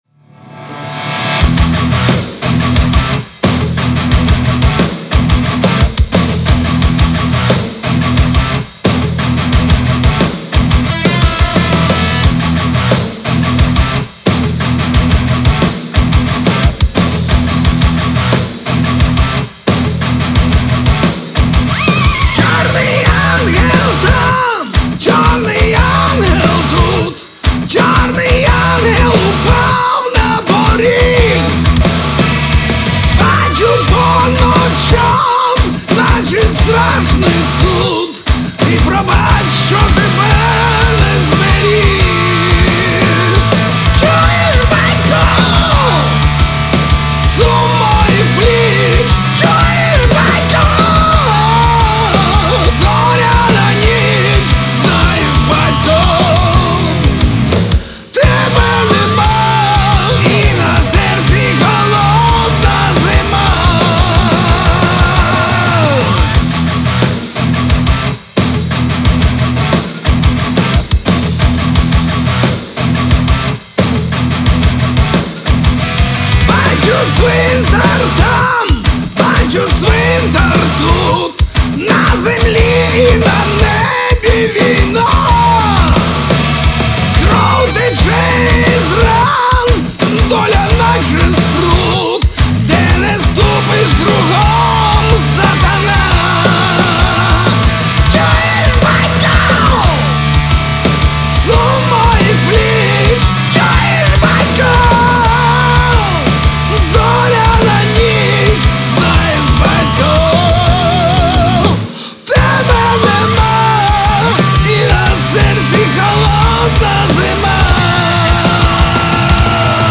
Hard Rock Heavy Metal